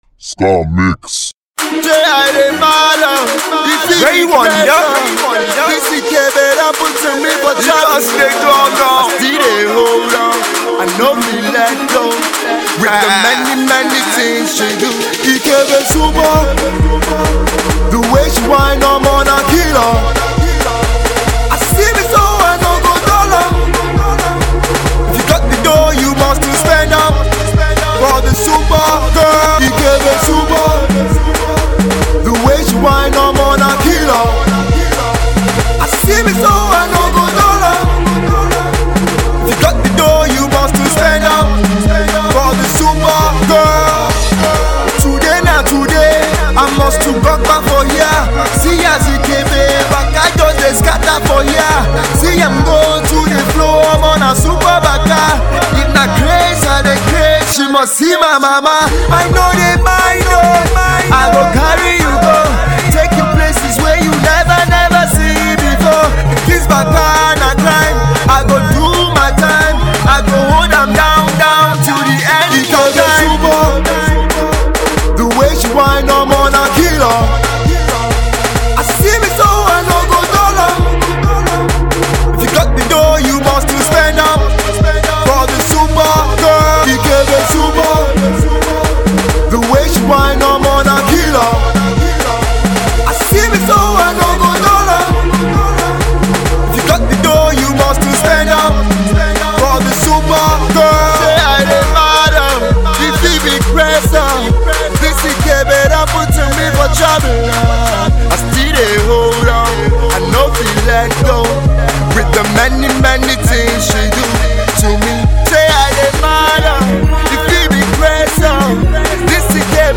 Afro DanceHall